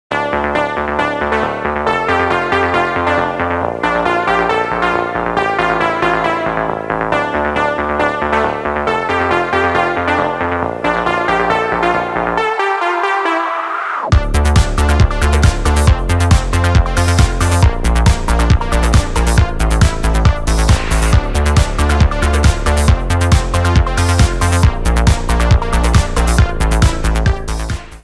инструментал , минус , поп